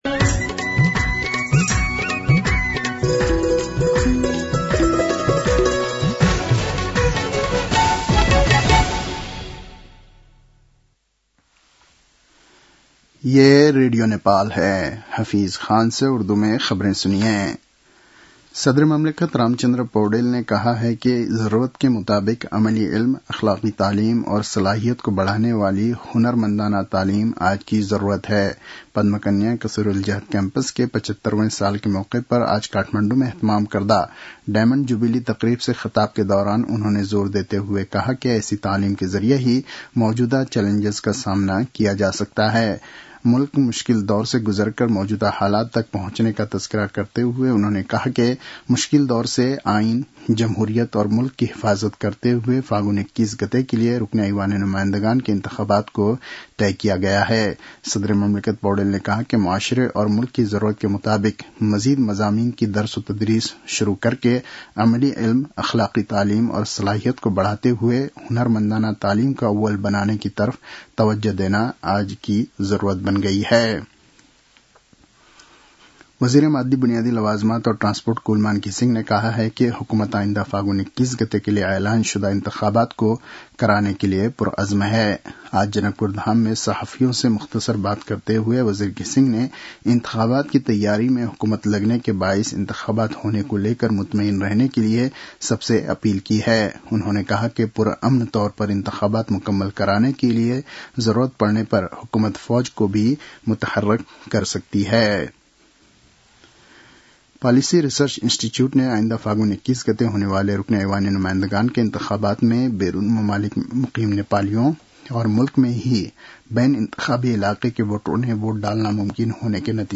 उर्दु भाषामा समाचार : ५ मंसिर , २०८२